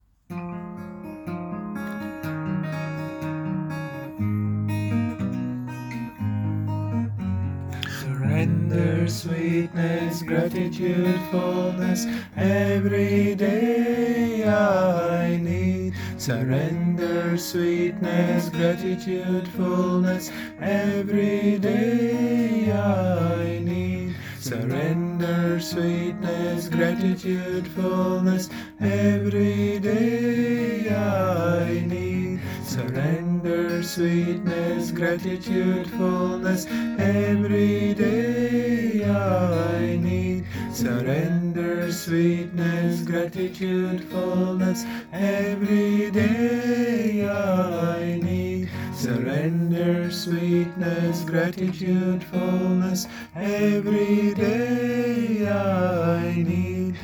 Here are 3 simple mantras we sang during the first evening of our festival of meditation – enjoy!
(apologies for quality – we realised we didn’t actually have a published version of this available so we just did something on the spot)